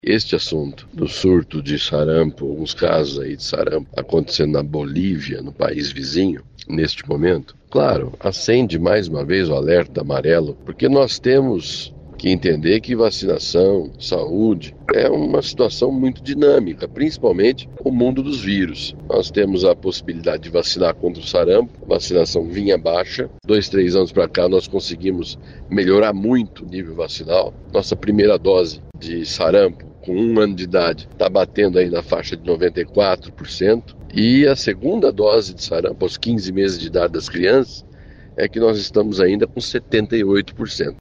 Segundo o secretário, a cobertura da segunda dose ainda está abaixo do ideal.